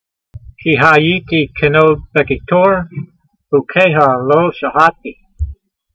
Sound (Psalm 119:83) Transliteration: kee-ha' yeetee ke nod bekee tor , hu key ha lo sha hah tee Vocabulary Guide: For I have become dried-out like a wine-skin in smoke : but I have not forgotten your statutes . Translation: For I have become dried-out like a wine-skin in smoke: but I have not forgotten your statutes.